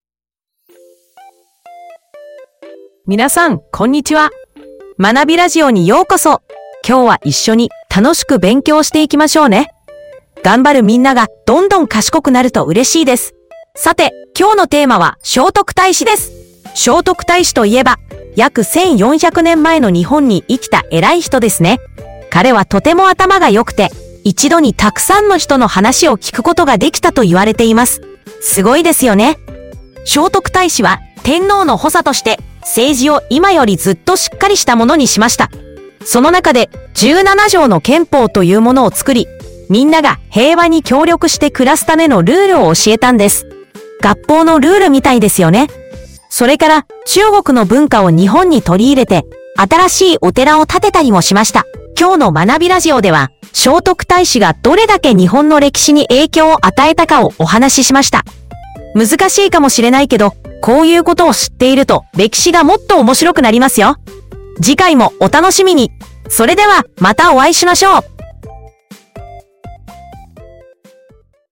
まなびラジオ」は、ユーザーがテーマを入力するだけで、生成AIがそのテーマに沿った読み上げ原稿とラジオ形式の音声メディアを自動生成します。